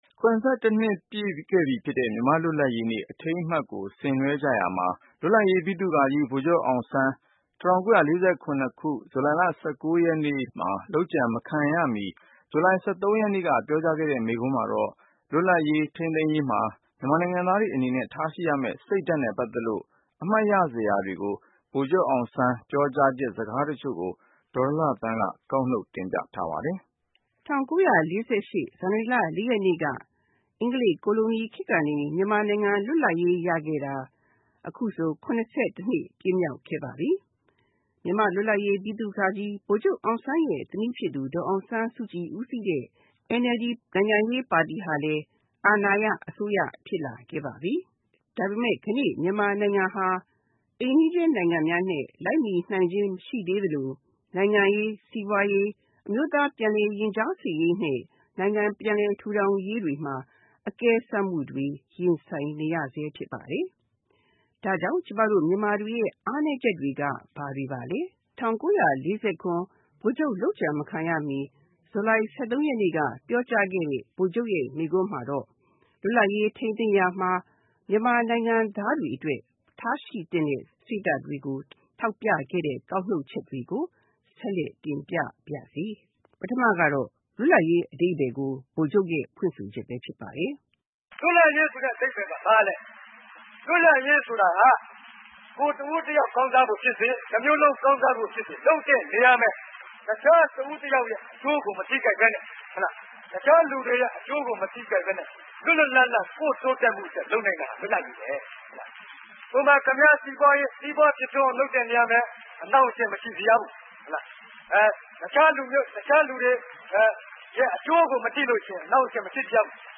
၇၁ နှစ်ပြည်ပြည့်ပြီဖြစ်တဲ့ မြန်မာ့လွတ်လပ်ရေးနေ့အထိမ်းအမတ်ကို ဆင်နွဲကြရမှာ လွတ်လပ်ရေး ဗိသုကာကြီး ဗိုလ်ချုပ်အောင်ဆန်း ၁၉၄၁ ခုနှစ် ဇူလိုင် ၁၃ ရက်နေ့က ပြောကြားခဲ့တဲ့ မိန့်ခွန်းမှာတော့ လွတ်လပ်ရေး ထိန်းသိမ်းရေးမှာ မြန်မာနိုင်ငံသားတွေအနေနဲ့ ထားရှိရမယ့် စိတ်ဓါတ်နဲ့ ပတ်သက်လို့ အမှတ်ရစရာတွေကို တင်ပြထားပါတယ်။